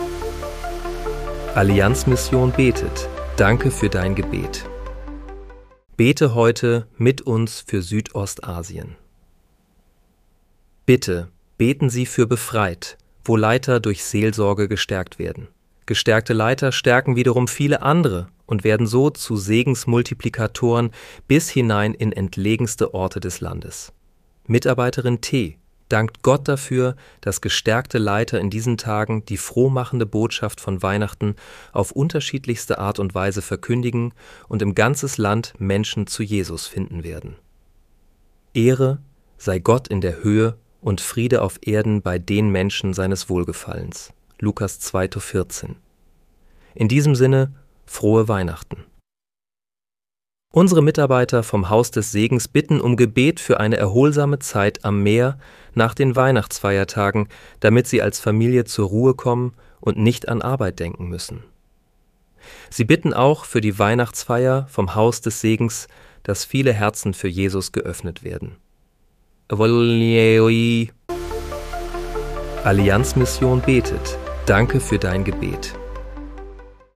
Bete am 26. Dezember 2025 mit uns für Südostasien. (KI-generiert